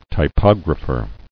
[ty·pog·ra·pher]